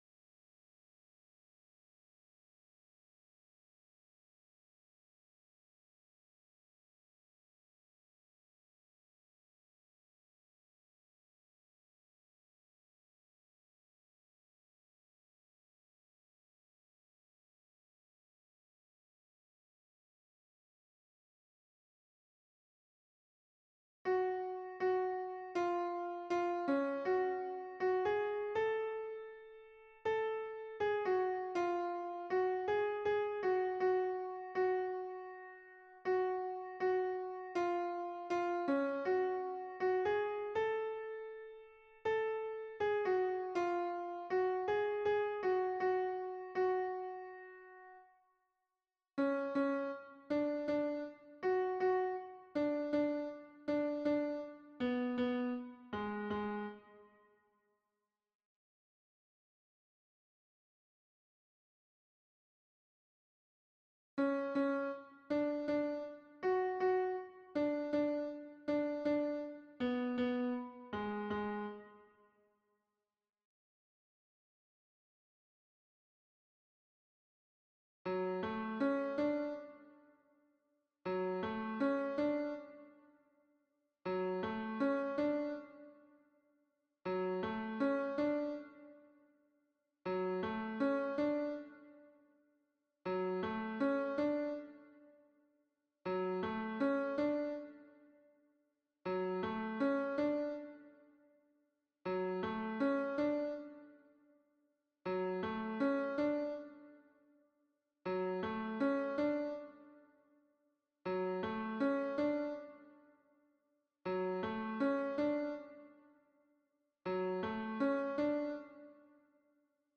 - berceuse traditionnelle norvégienne
MP3 version piano
Ténor